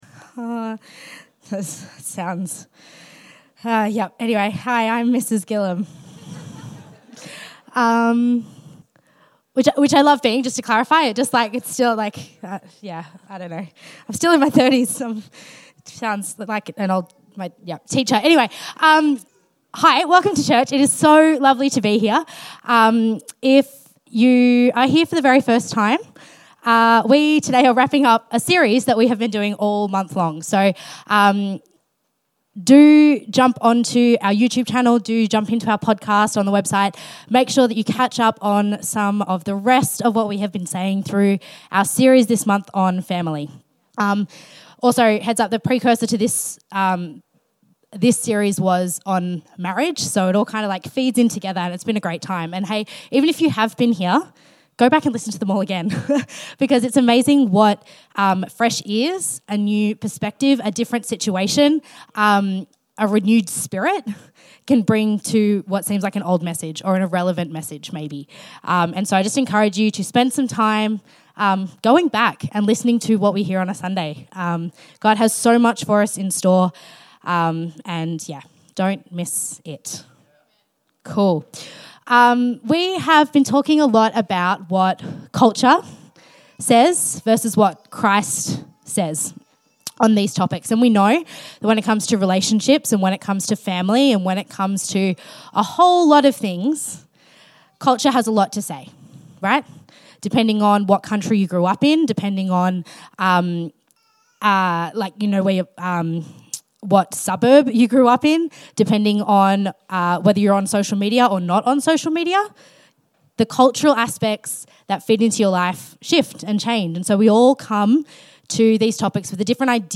Infinity Church Podcast - English Service | Infinity Church
Current Sermon